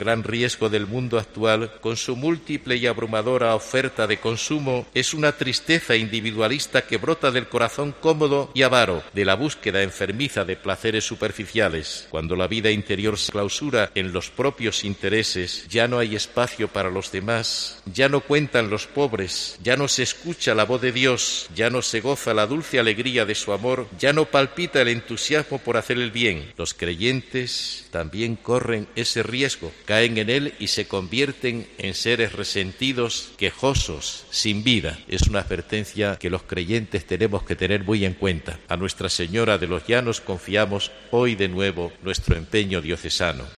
Ciriaco Benavente, obispo de Albacete
catedral de Albacete en el dia de la patrona
Pueden escucharse tres fragmentos de la homilia en la Eucaristía celebrada con motiivo de la festividad de la Virgen de los Llanos, patrona de Albacete, a la que han asistido cientos de albaceteños, así como representantes de todas las instituciones civiles y militares de Albacete.